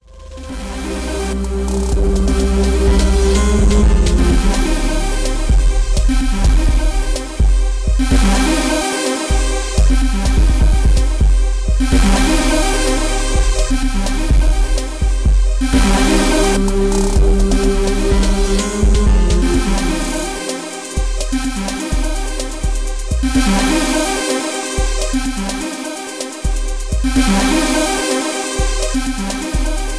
rap beat